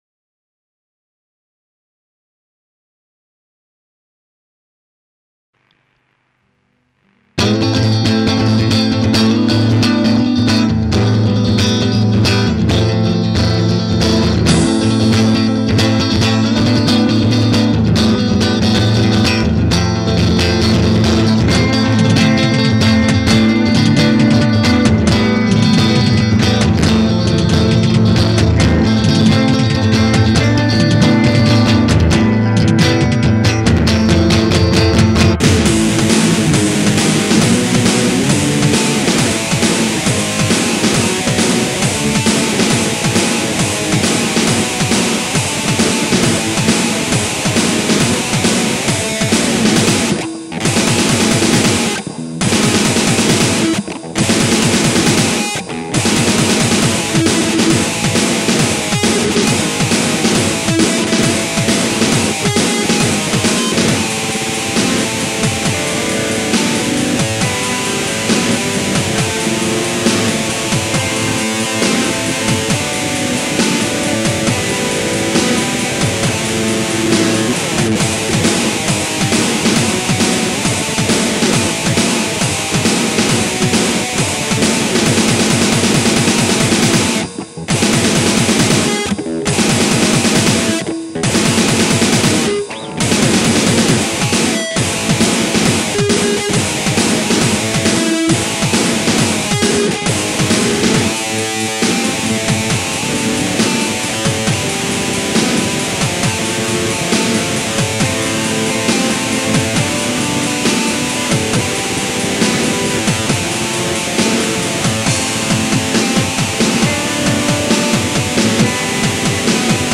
Batterie : Guitar Pro 4